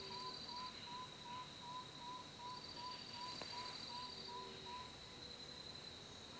Spotted Bamboowren (Psilorhamphus guttatus)
Life Stage: Adult
Location or protected area: Bio Reserva Karadya
Condition: Wild
Certainty: Recorded vocal
gallito-overo.mp3